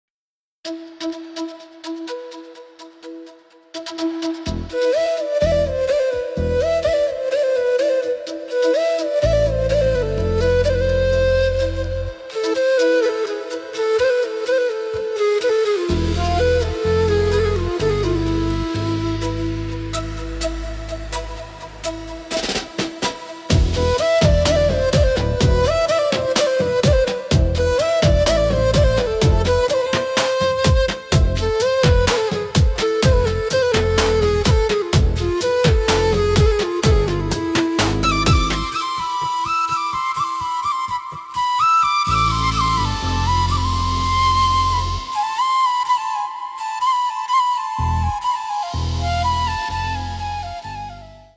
heart-touching emotion